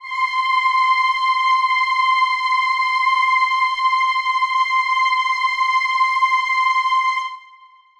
Choir Piano (Wav)
C6.wav